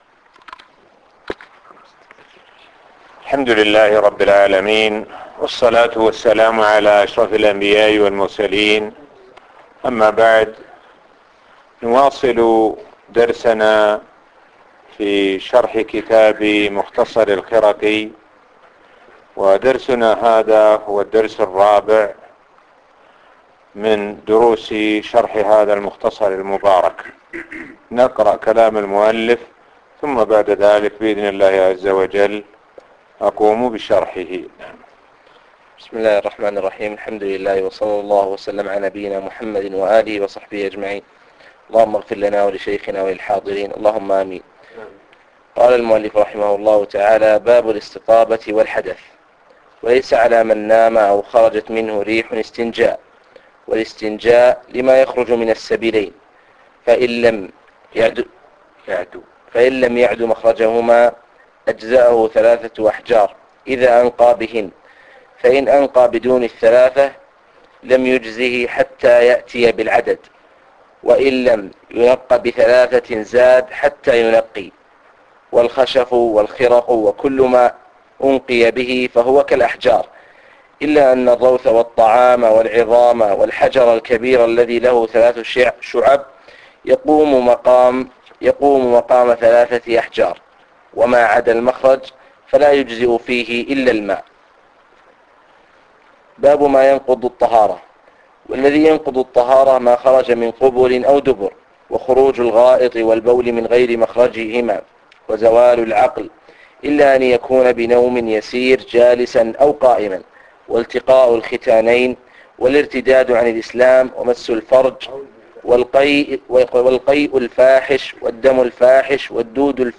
الموقع الرسمي لفضيلة الشيخ الدكتور سعد بن ناصر الشثرى | الدرس---4 باب الاستطابة والحدث